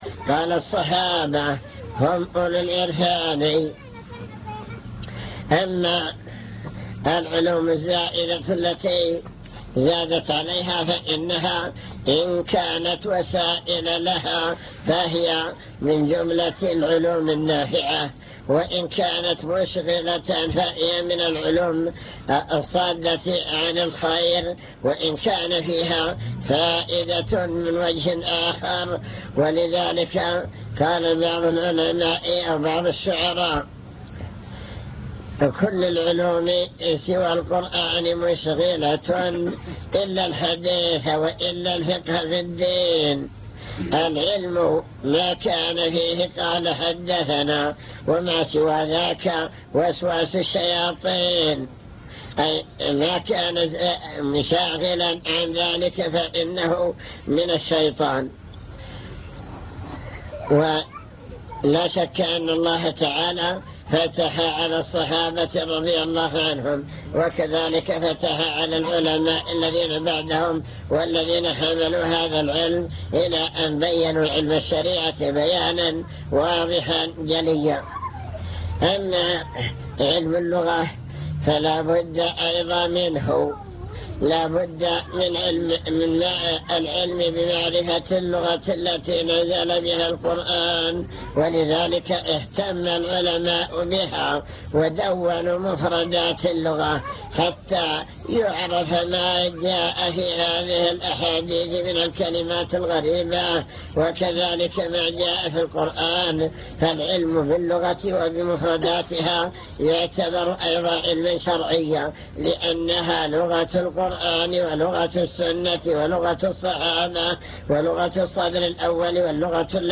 المكتبة الصوتية  تسجيلات - محاضرات ودروس  محاضرات عن طلب العلم وفضل العلماء بحث في: أهم المسائل التي يجب على المسلم تعلمها